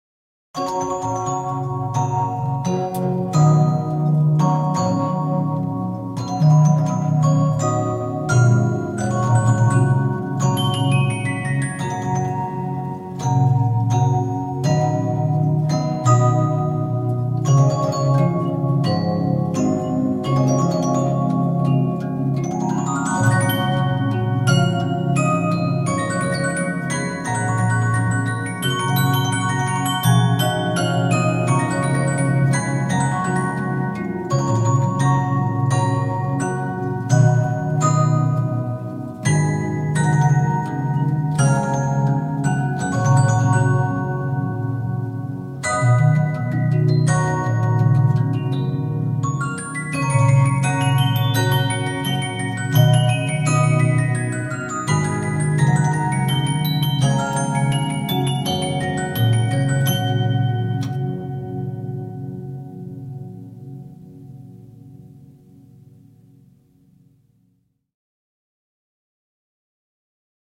They are both rendered on music boxes dating back to the 1890’s.
And second, “Come Ye Disconsolate” played on a 17 inch Stella music box:
Stella 17 inch console